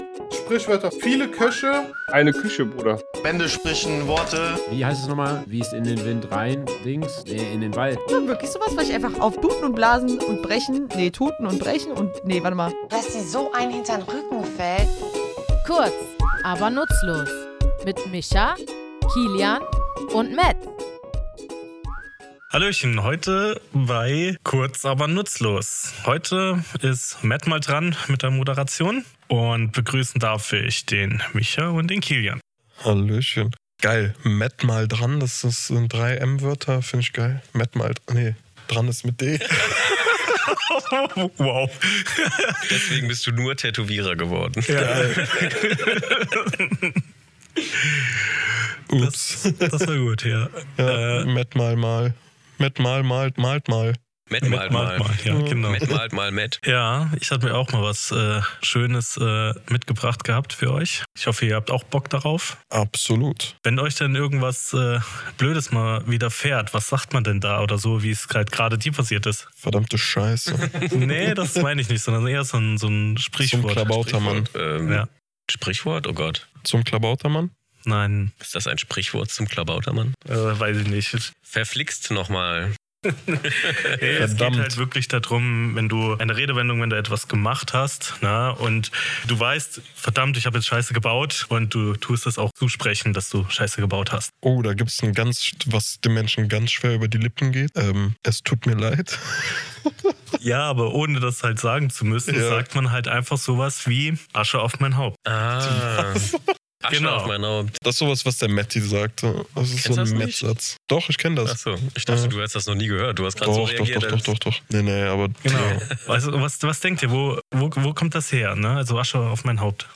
Comedy
drei tätowierende Sprachfans, nehmen euch in unserem Tattoostudio